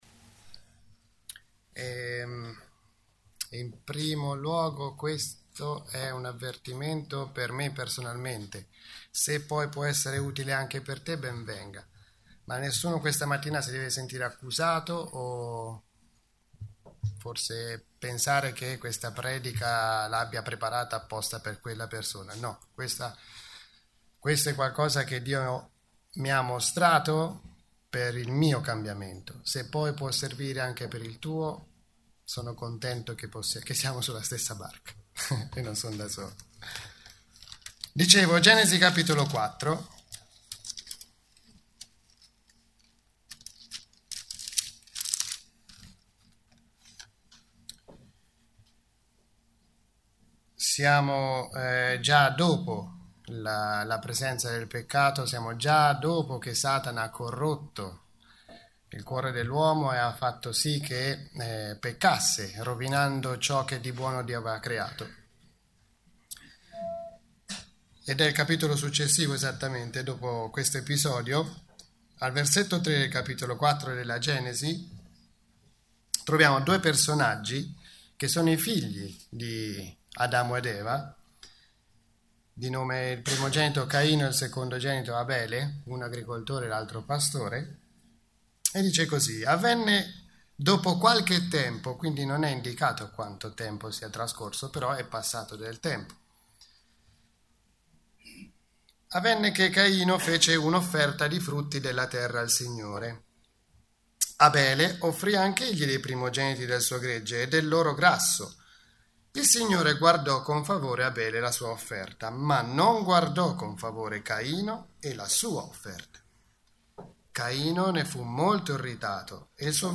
Tipo Di Incontro: Domenica